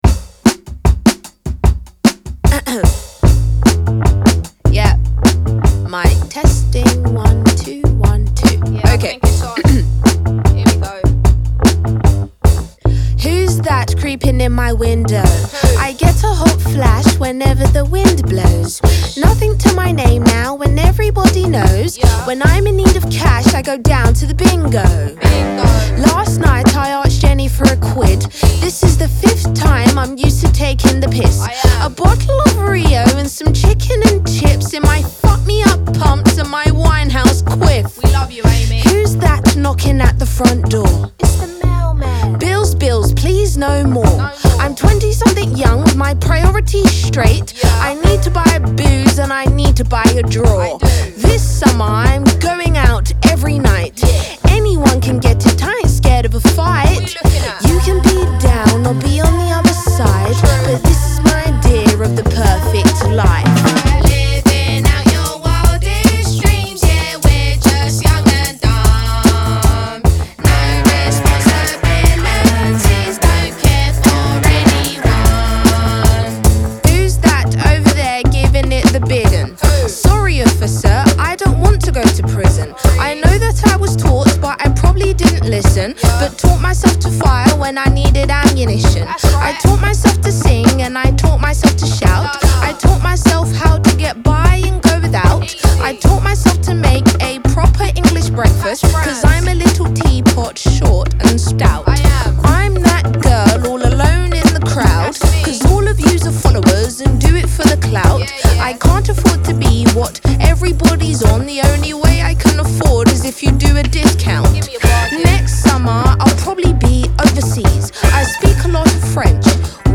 • Жанр: Pop, Indie